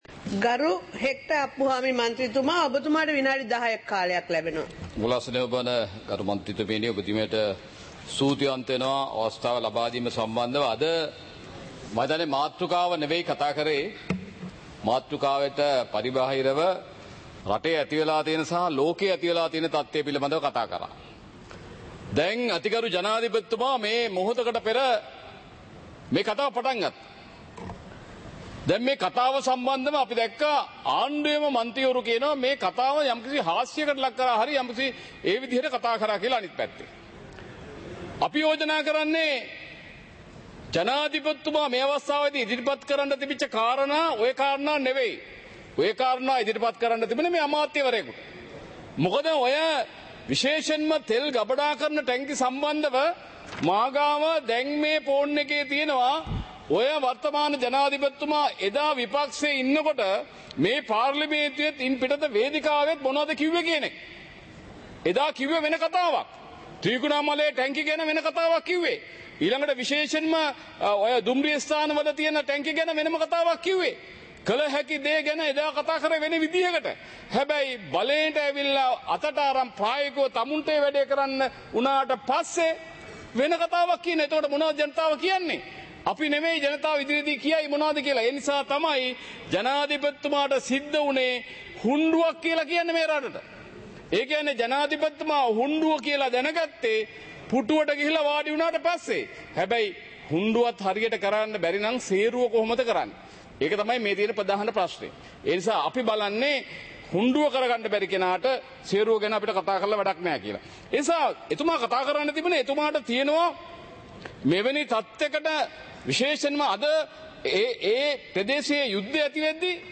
சபை நடவடிக்கைமுறை (2026-03-03)